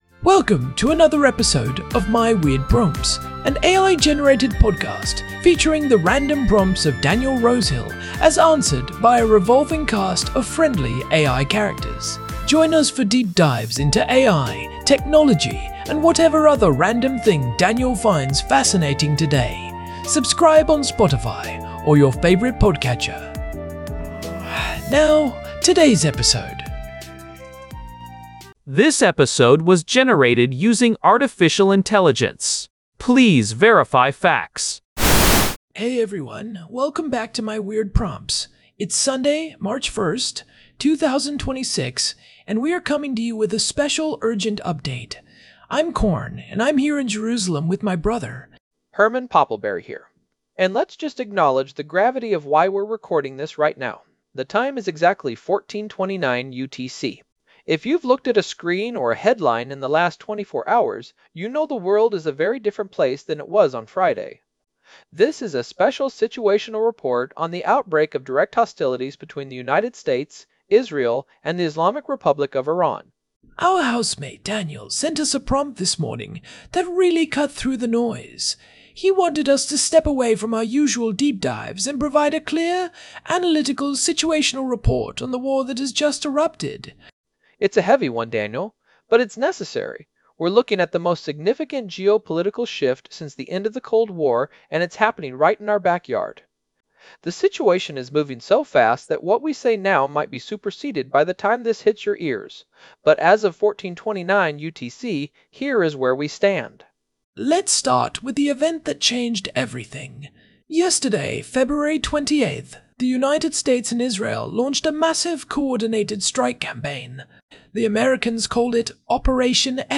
SPECIAL SITREP EPISODE - FOLLOW-UP SITUATIONAL UPDATE